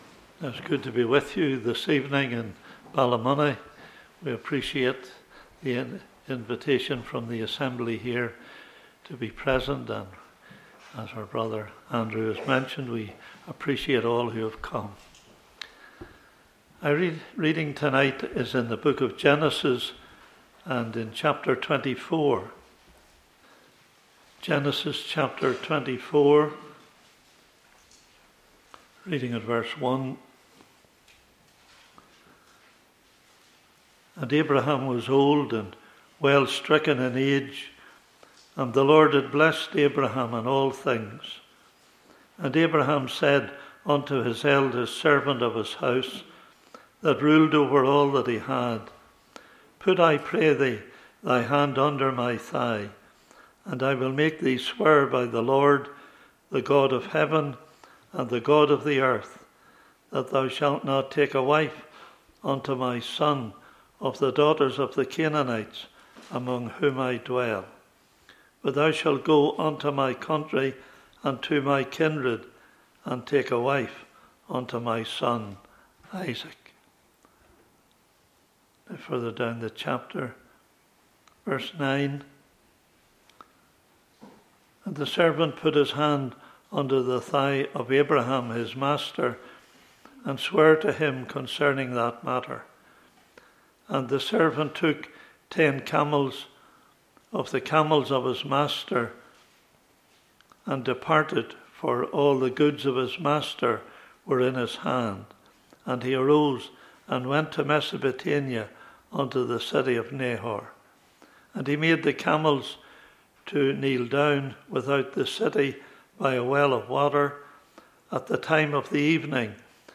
Passage: Genesis 24 Meeting Type: Ministry